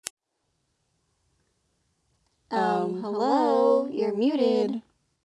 Play, download and share You're Muted! original sound button!!!!